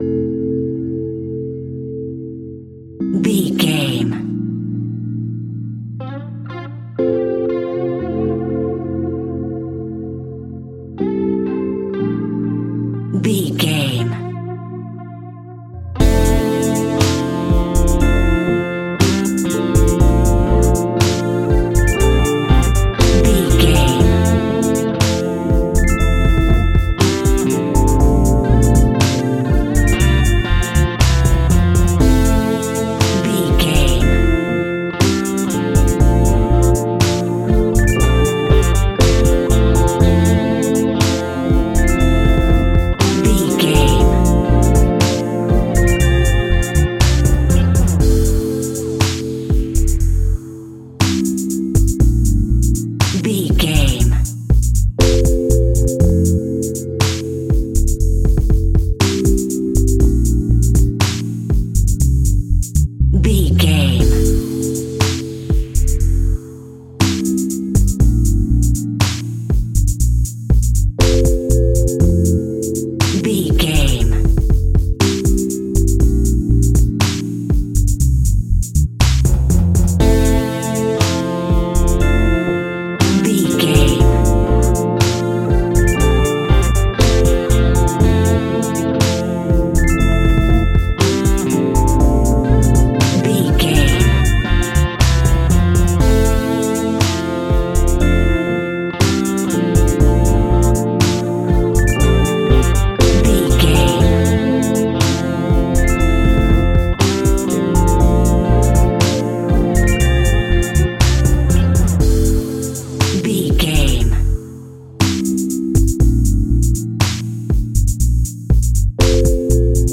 Ionian/Major
laid back
Lounge
sparse
new age
chilled electronica
ambient
atmospheric
morphing